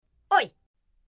Звуки ой
Подростковый